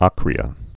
(ŏkrē-ə)